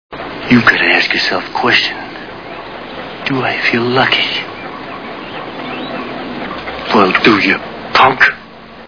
Dirty Harry Movie Sound Bites
Dirty Harry Sound Bites